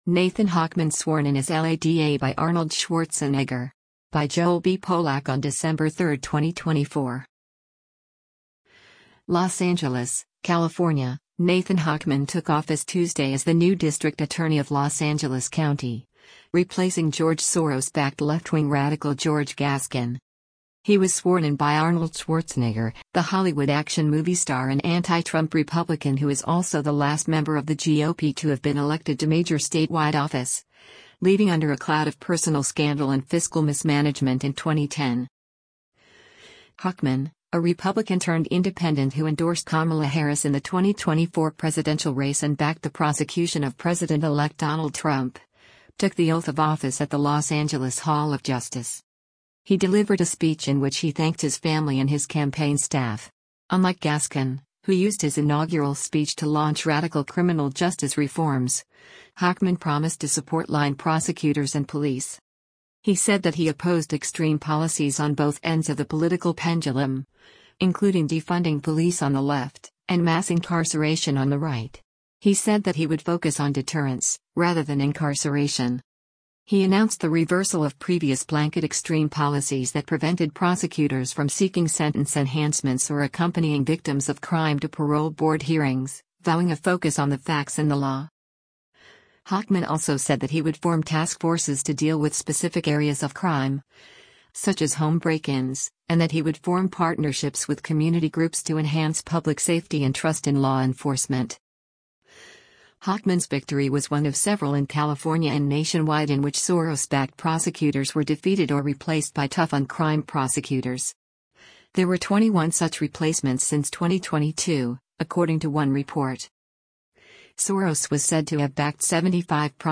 Hochman, a Republican-turned-independent who endorsed Kamala Harris in the 2024 presidential race and backed the prosecution of President-elect Donald Trump, took the oath of office at the Los Angeles Hall of Justice.
He delivered a speech in which he thanked his family and his campaign staff.